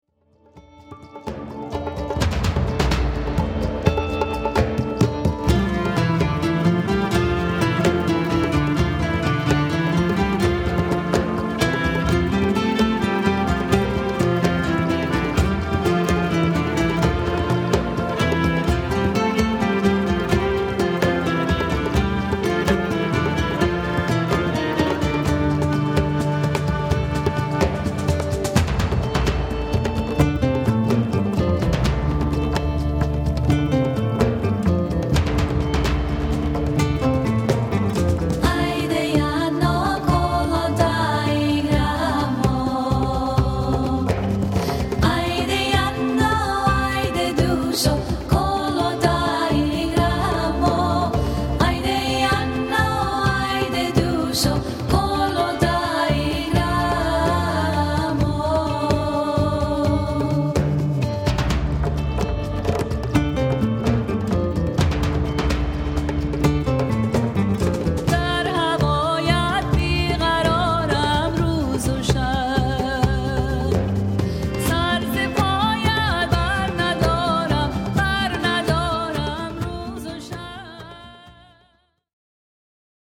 powerful traditional melodies